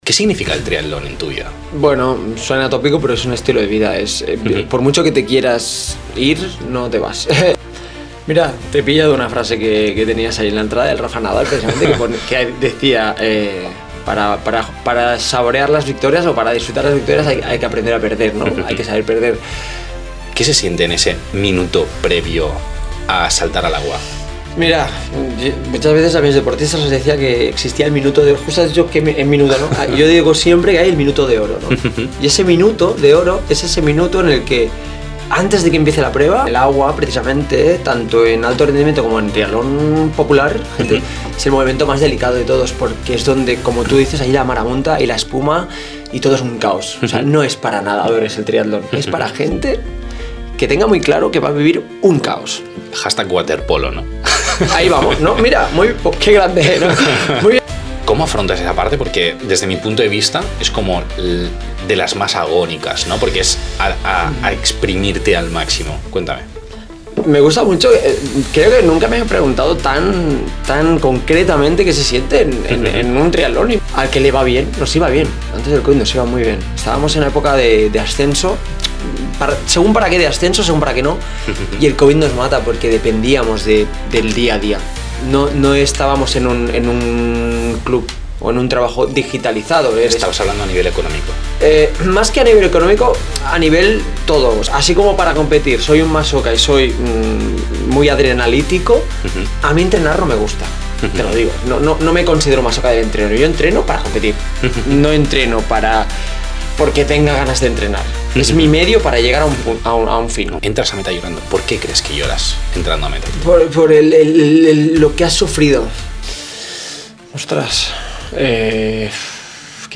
Una charla sobre esfuerzo real, liderazgo y la pasión que mueve al triatlón.